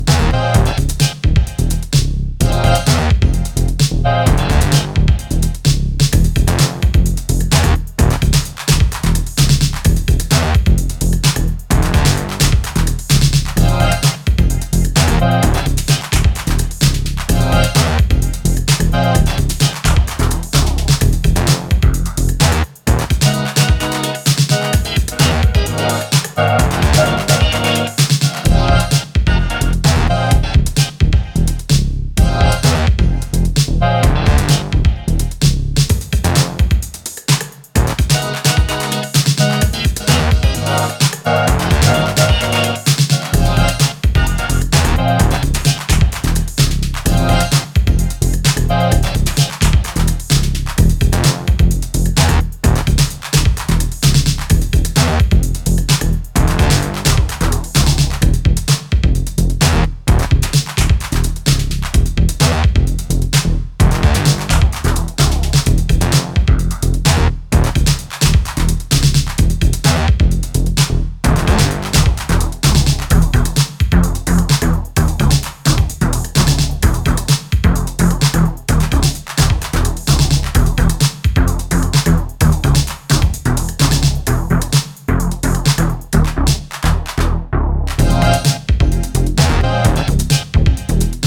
supplier of essential dance music
Electro Techno